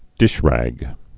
(dĭshrăg)